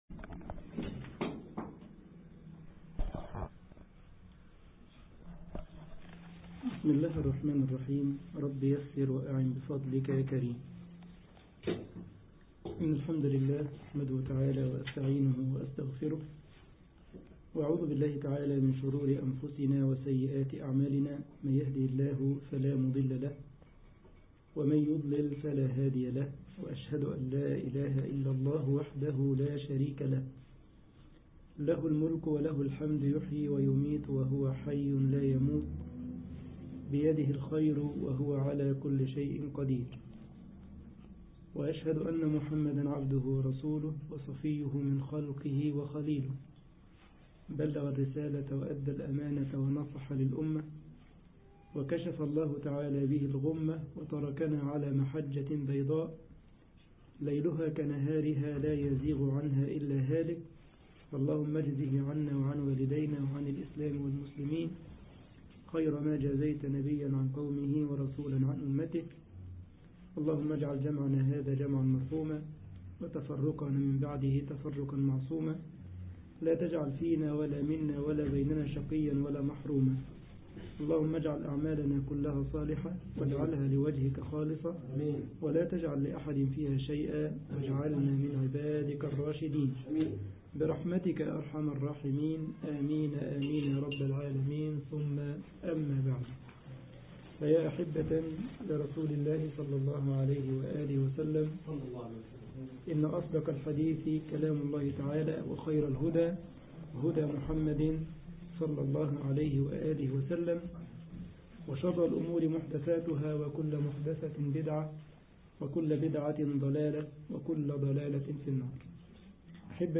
مصلى جامعة السارلند ـ ألمانيا